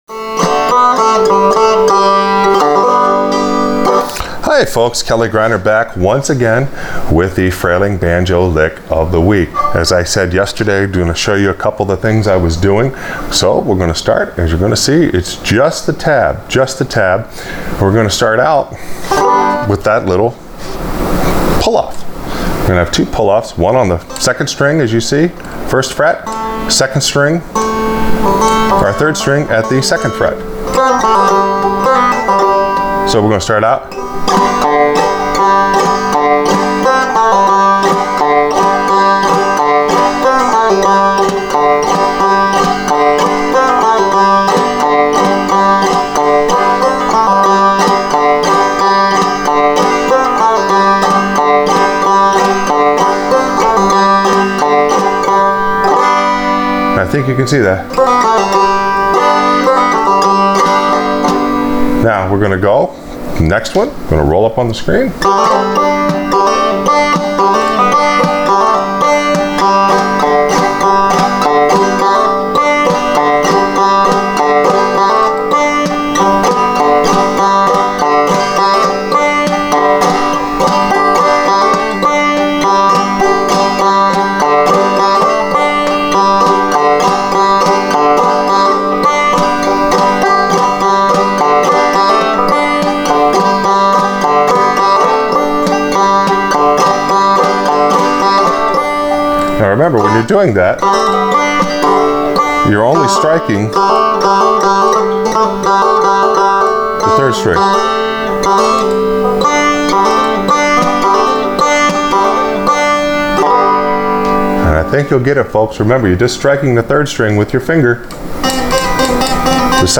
Frailing Banjo Lick Of The Week – Pull Offs and Phantom Hammer Ons
The tab is for the pull offs on the 2nd and 3rd strings and the Phantom Hammer-ons on also on the 3rd and 2nd strings.